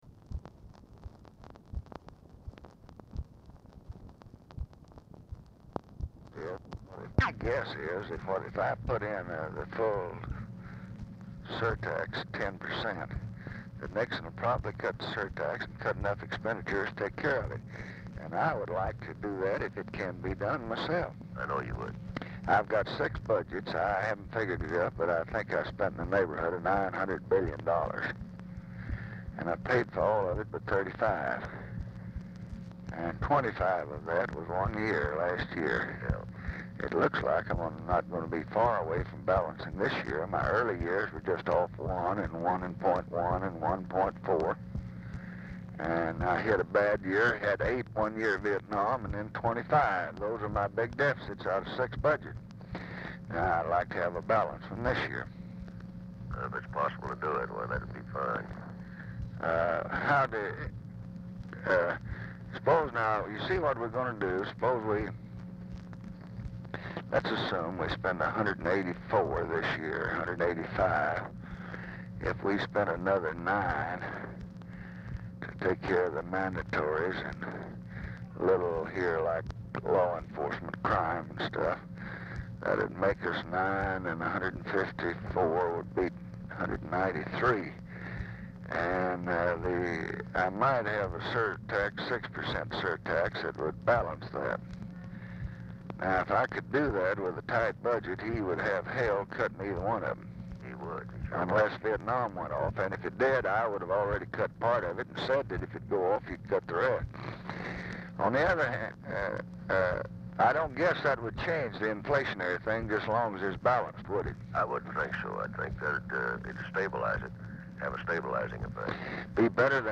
Telephone conversation # 13810, sound recording, LBJ and WILBUR MILLS, 12/16/1968, 10:00AM
MILLS' GRANDCHILD IS AUDIBLE IN BACKGROUND
Dictation belt